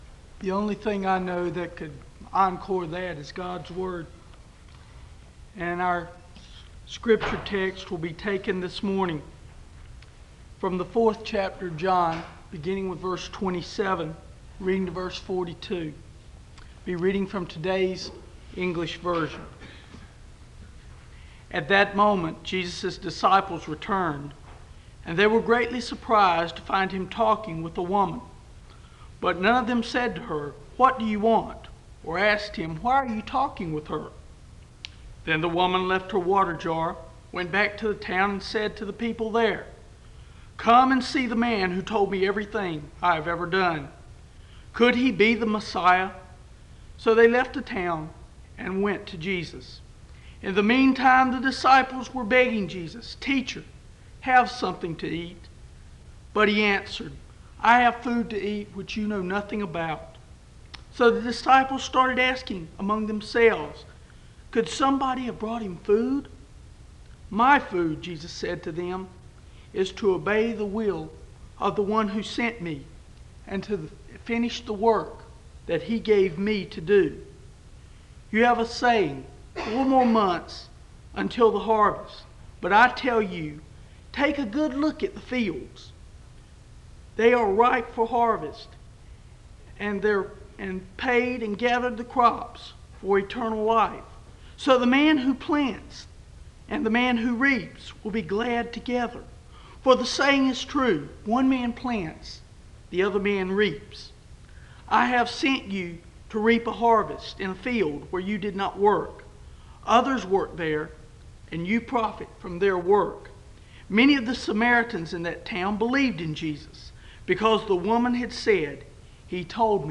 The service begins with the speaker reading from John 4:27-42 (00:00-02:33). A word of prayer is given (02:34-04:55).